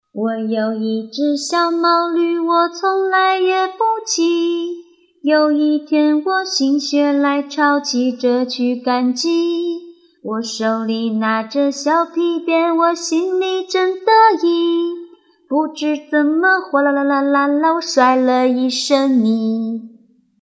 I Have a Small Donkey - Taiwanese Children's Songs - Taiwan - Mama Lisa's World: Children's Songs and Rhymes from Around the World